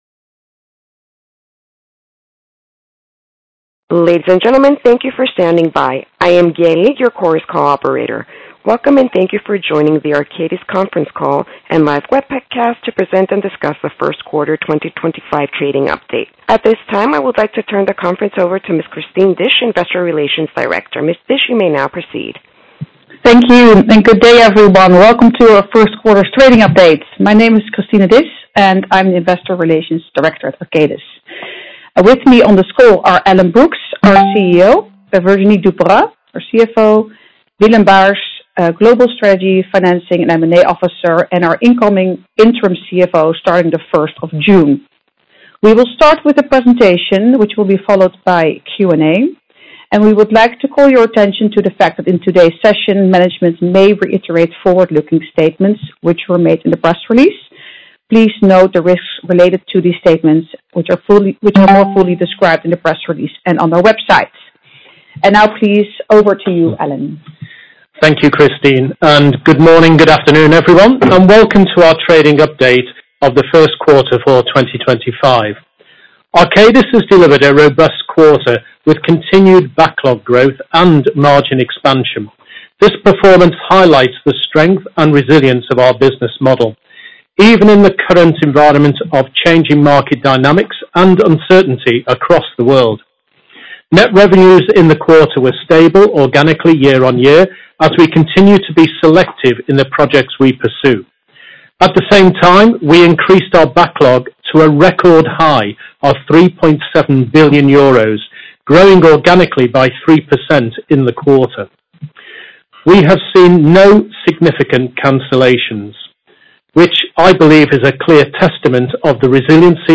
Arcadis NV (OTCPK:ARCVF) Q1 2025 Earnings Call May 7, 2025 8:00 AM ET